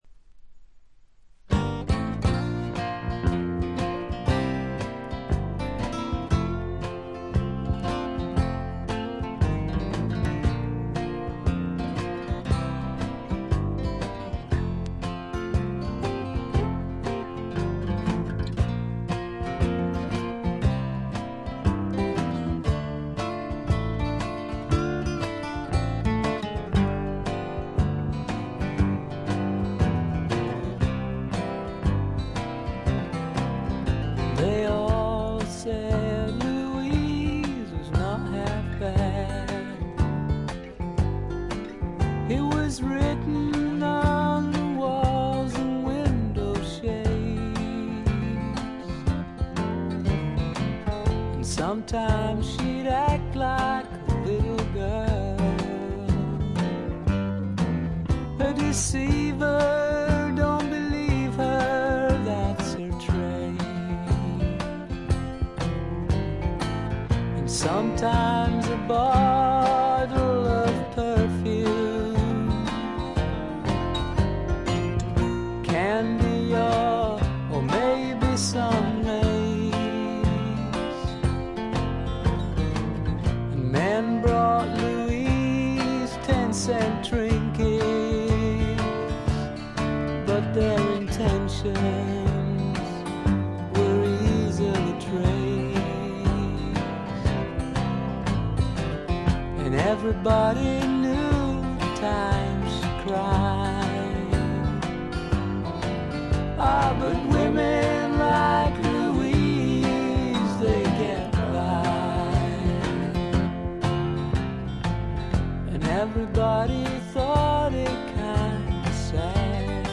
プカプカと牧歌的で枯れた味わいです。
mandolin, cello, mandola